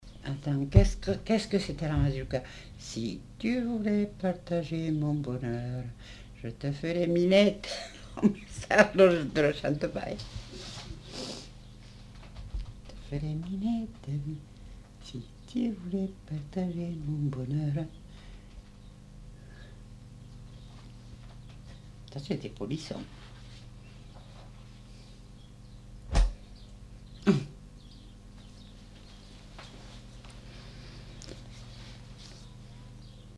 Aire culturelle : Comminges
Lieu : Montauban-de-Luchon
Genre : chant
Effectif : 1
Type de voix : voix de femme
Production du son : chanté
Danse : mazurka